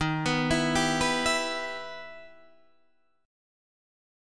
Em/Eb chord